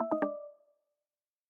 LowBattery.ogg